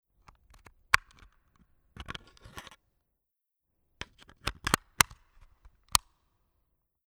Akkufach öffnen und schliessen
1316_Akkufach_oeffnen_und_schliessen.mp3